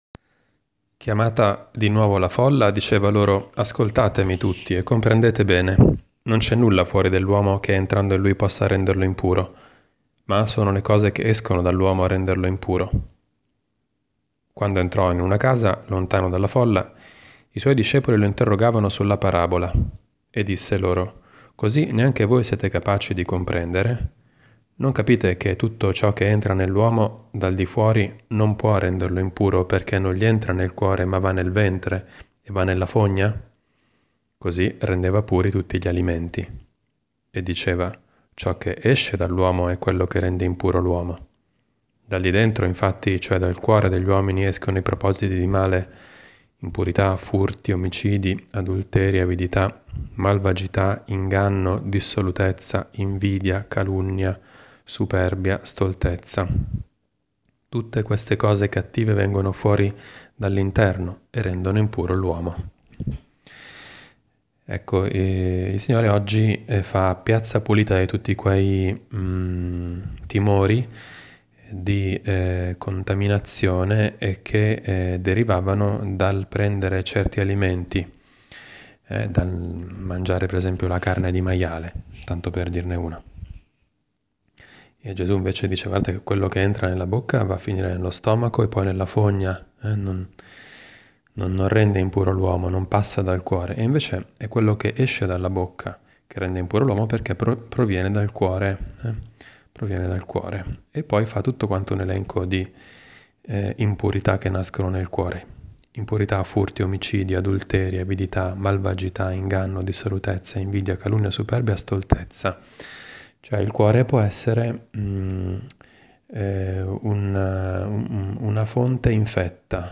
Commento al vangelo (Mc 7,14-23) del 7 febbraio 2018, mercoledì della V settimana del Tempo Ordinario.